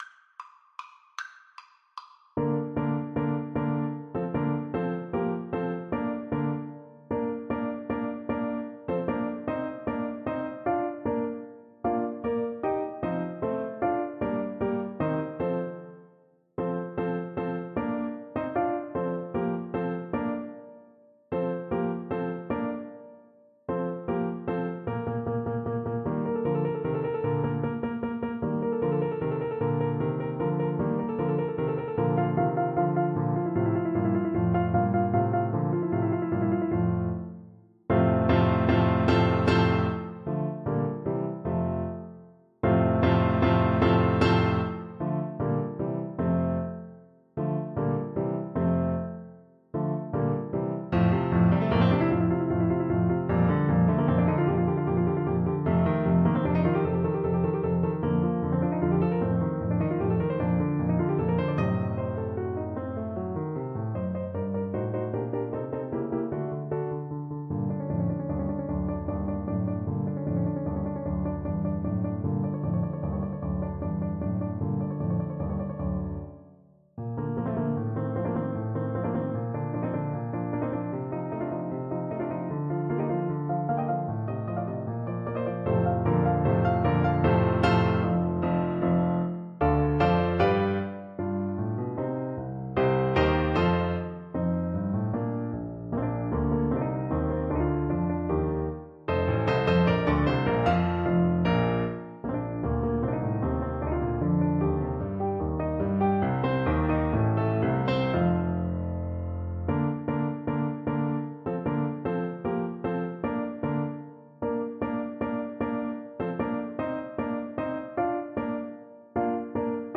Allegretto = c. 152
3/4 (View more 3/4 Music)
Classical (View more Classical Tuba Music)